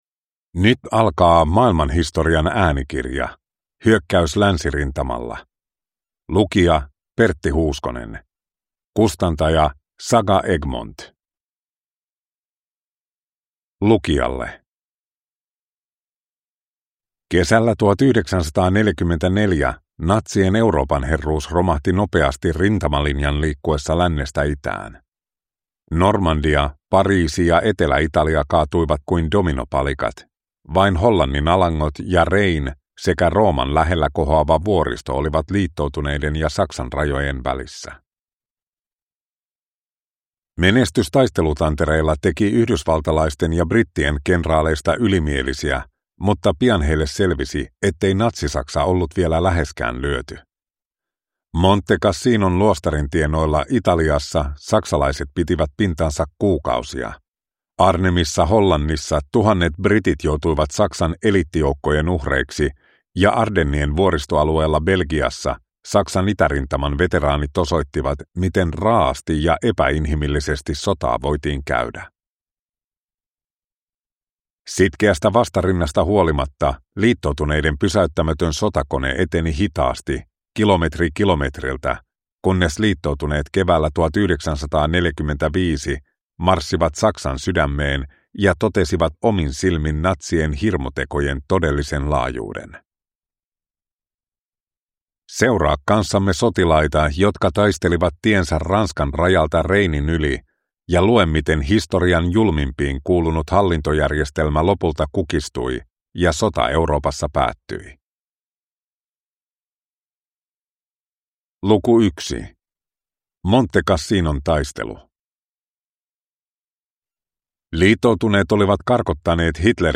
Hyökkäys länsirintamalla – Ljudbok